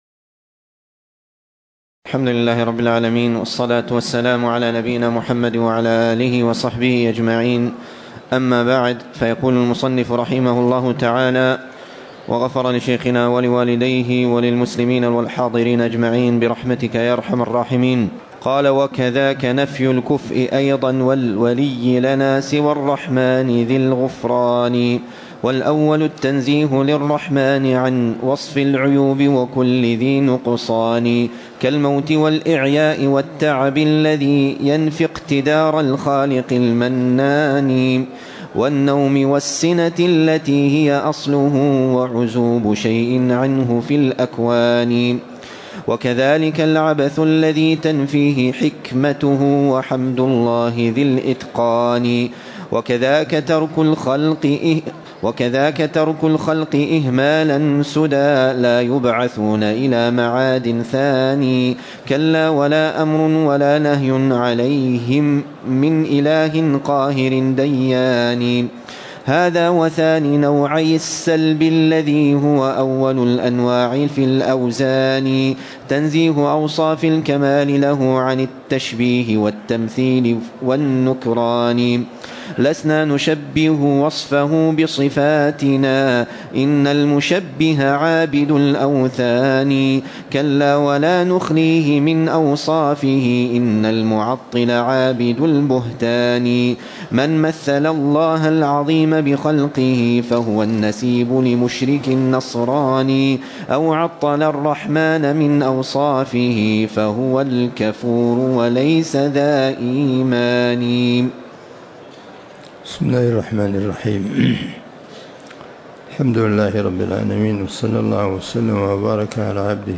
تاريخ النشر ١٠ جمادى الأولى ١٤٤١ هـ المكان: المسجد النبوي الشيخ